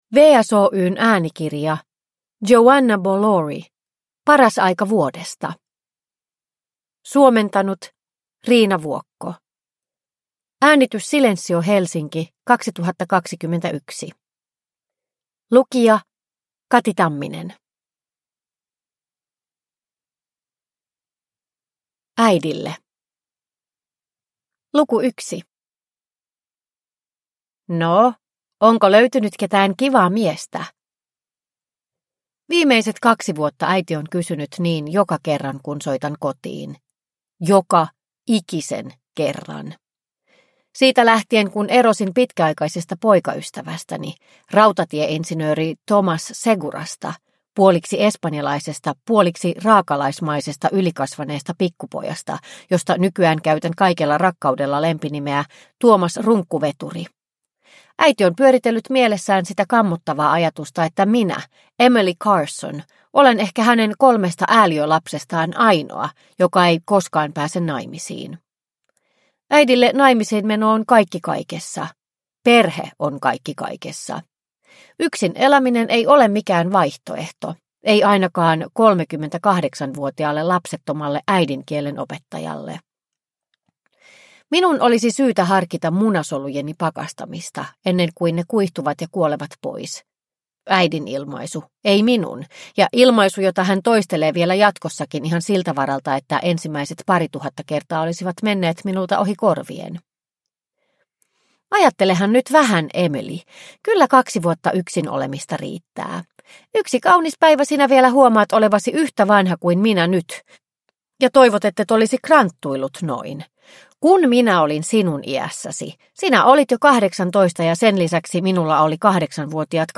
Paras aika vuodesta – Ljudbok – Laddas ner